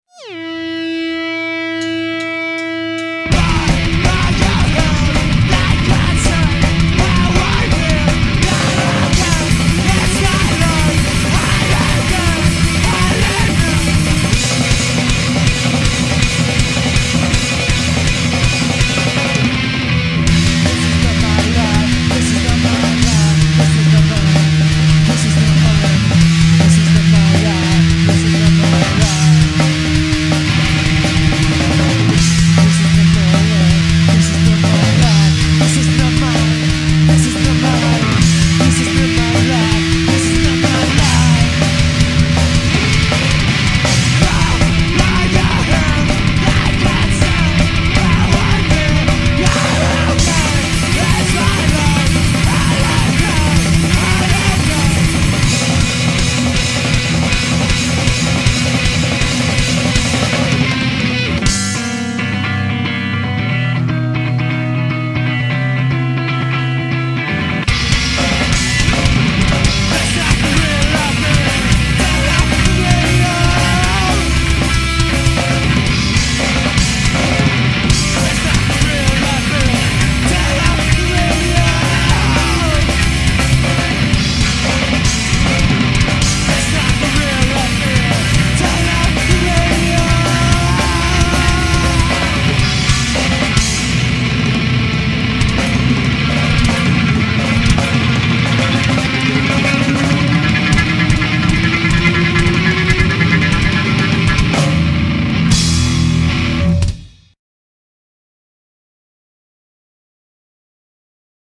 Vocals & Guitar
Drums
Bass & Vocals